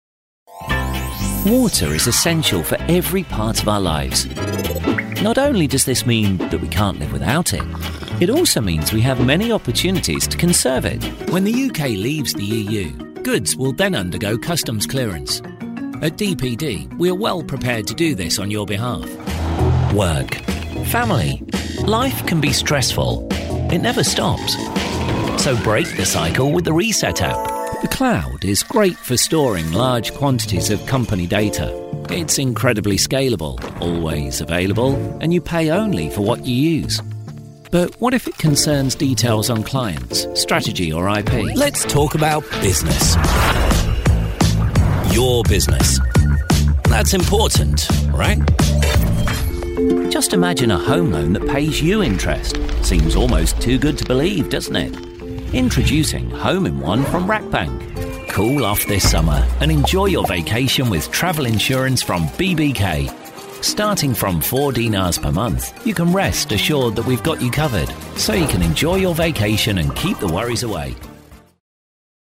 Explaining the Explainer video voice
Need a clear, friendly explainer voice?